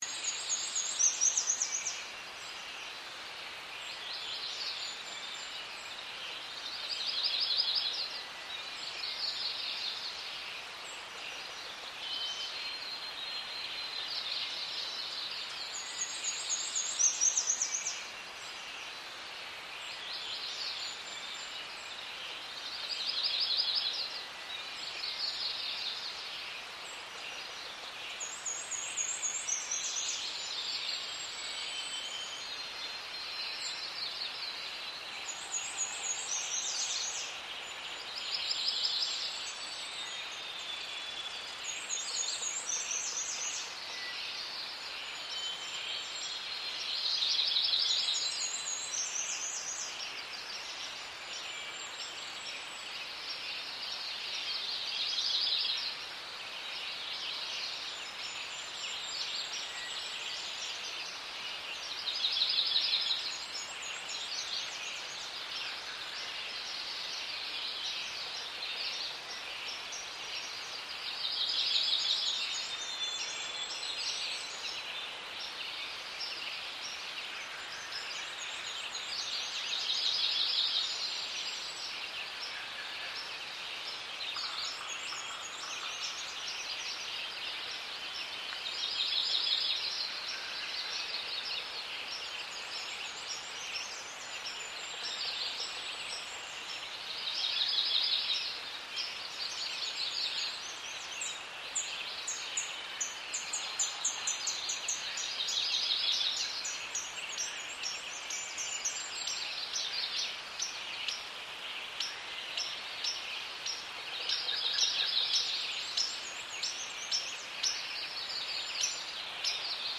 Forest.mp3